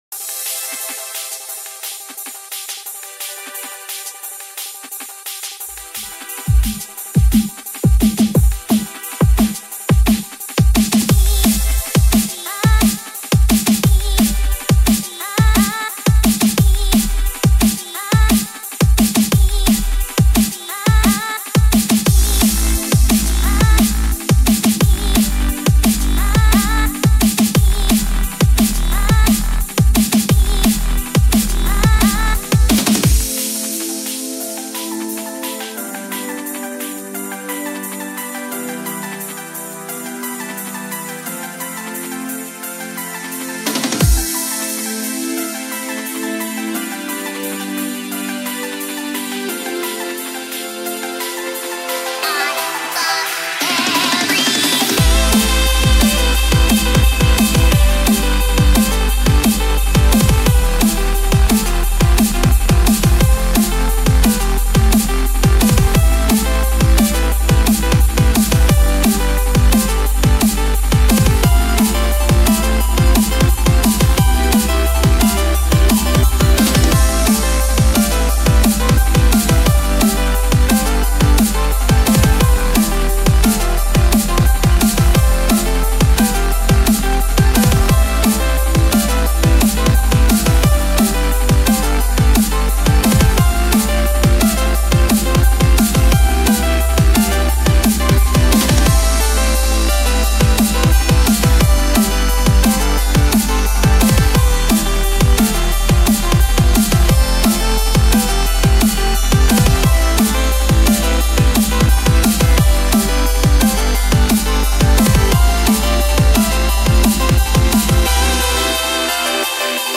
Drum and Bass